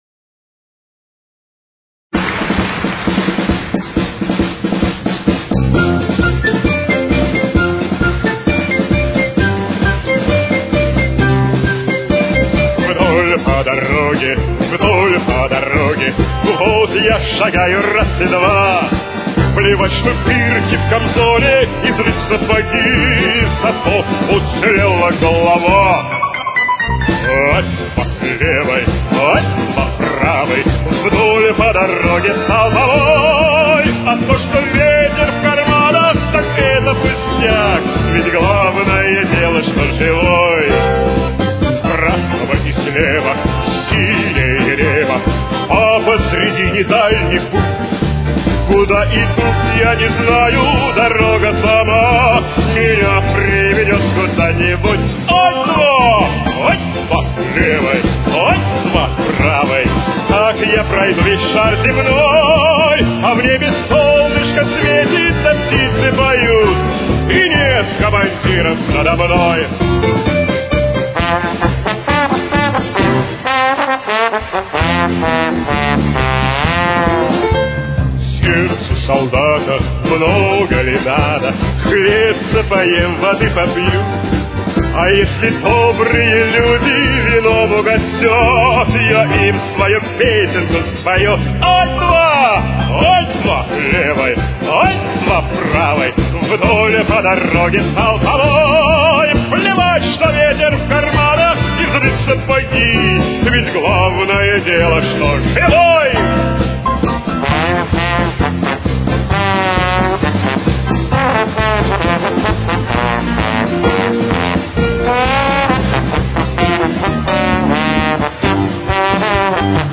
Темп: 135.